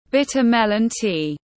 Trà khổ qua tiếng anh gọi là bitter melon tea, phiên âm tiếng anh đọc là /’bitə ‘melən ti:/
Bitter melon tea /’bitə ‘melən ti:/